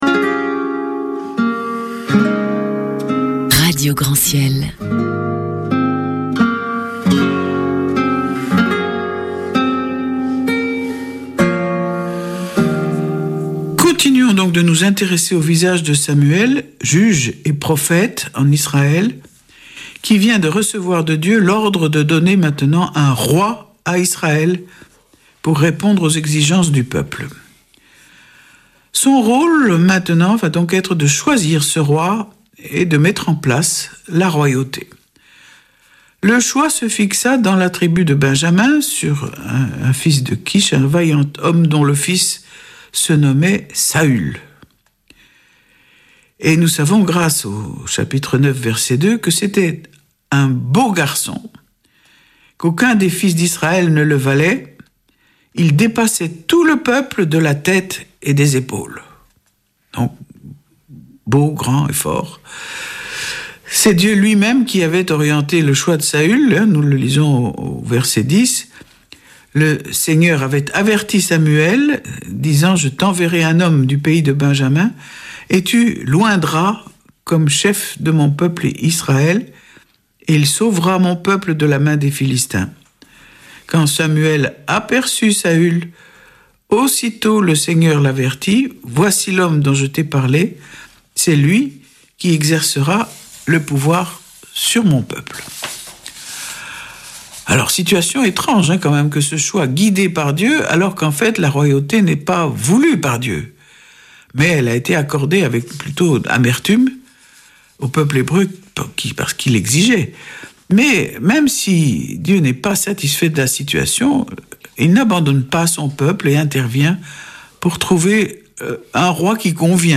Vêpres de Saint Sernin du 17 août
Une émission présentée par Schola Saint Sernin Chanteurs